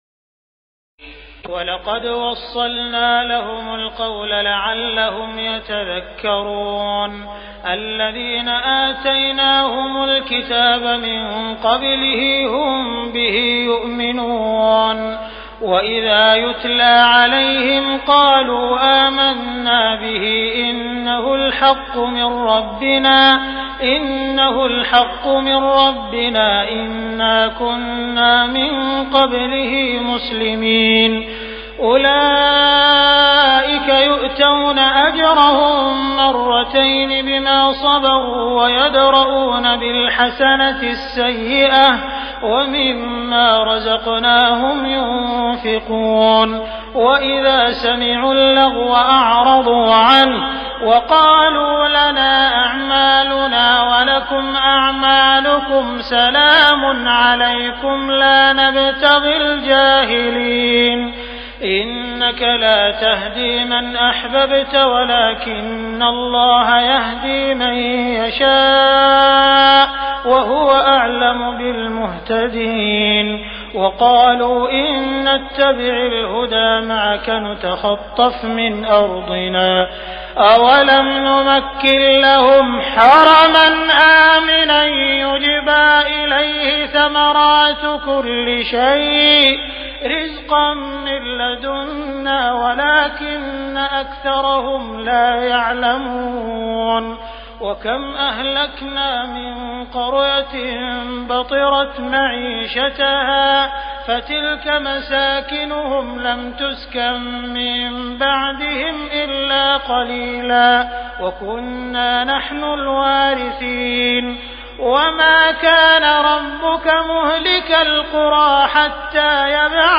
تراويح الليلة التاسعة عشر رمضان 1418هـ من سورتي القصص (51-88) والعنكبوت (1-45) Taraweeh 19 st night Ramadan 1418H from Surah Al-Qasas and Al-Ankaboot > تراويح الحرم المكي عام 1418 🕋 > التراويح - تلاوات الحرمين